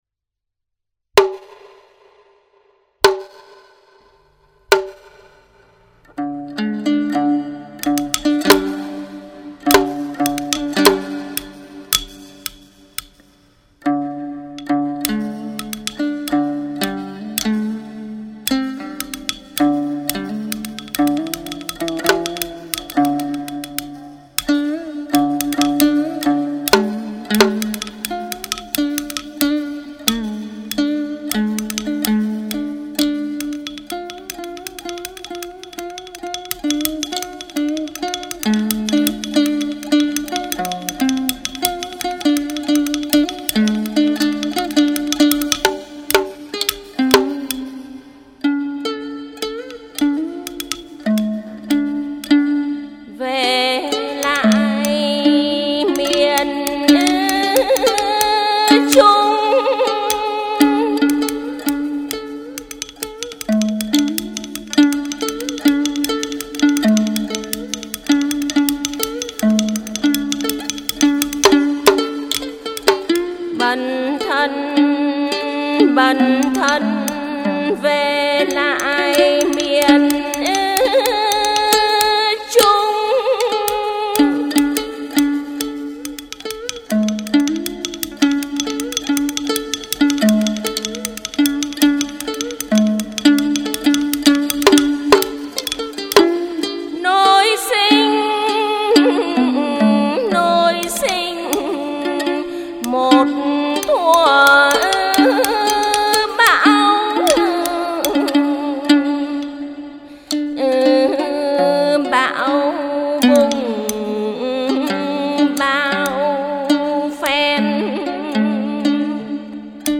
Thể loại: Ca trù